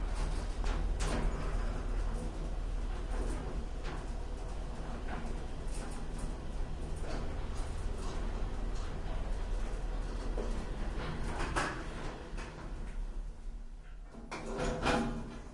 电梯03
描述：在一栋大楼的电梯内上上下下的录音。索尼PCMD50
Tag: 关闭 关闭 电梯 电梯 机械 开盘